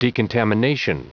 Prononciation du mot decontamination en anglais (fichier audio)
Prononciation du mot : decontamination